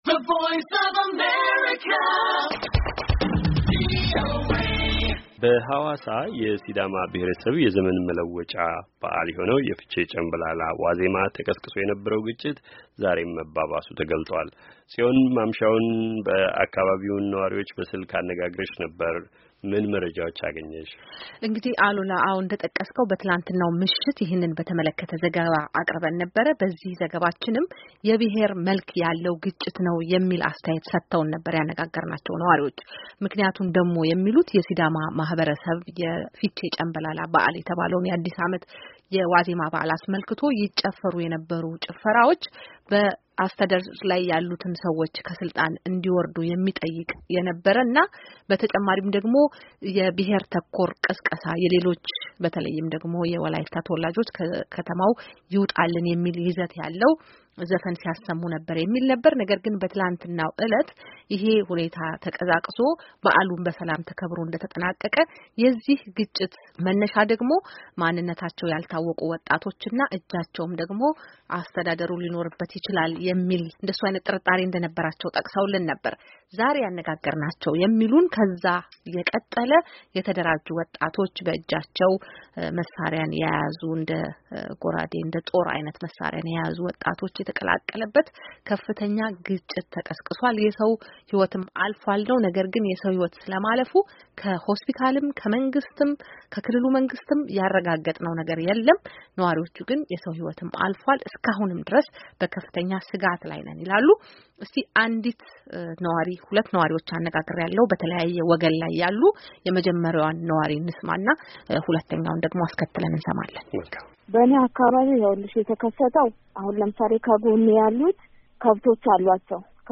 የሀዋሳ ከተማ የነዋሪዎች ግጭት - ነዋሪዎች አነጋግረናል
ዜና